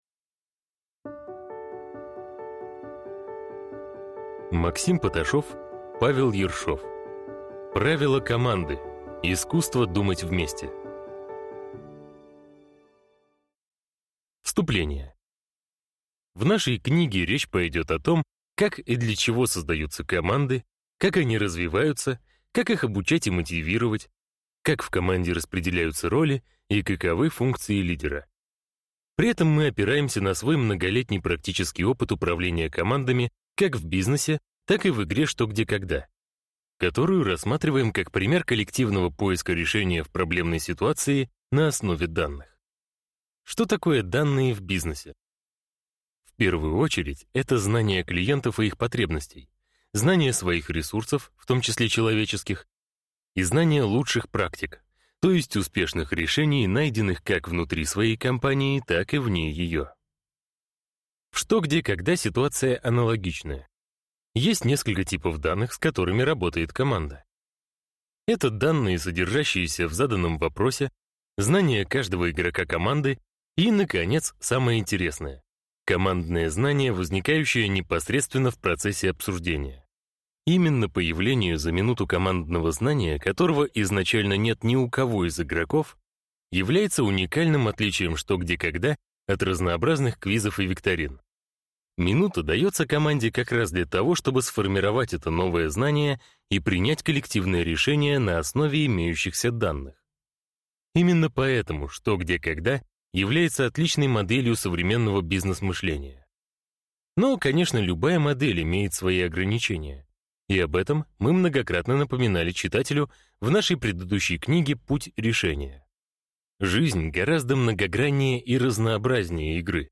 Аудиокнига Правила команды. Искусство думать вместе | Библиотека аудиокниг